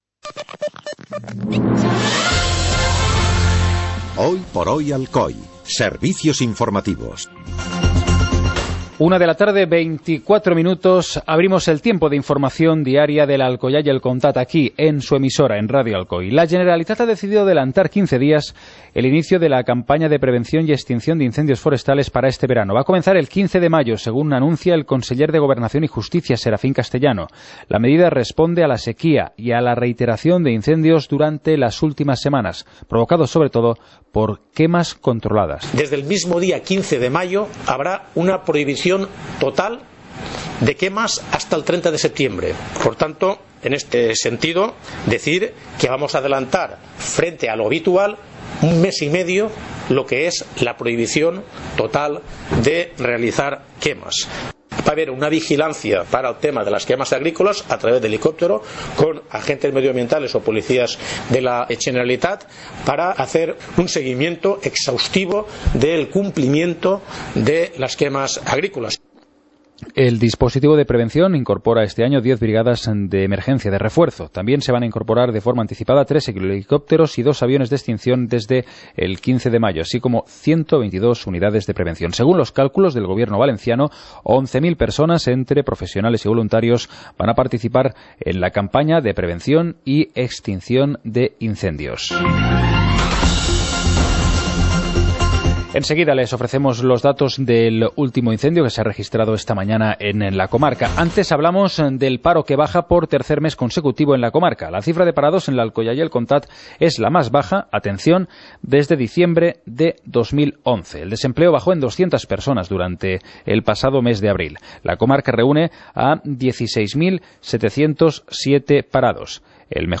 Informativo comarcal - miércoles, 07 de mayo de 2014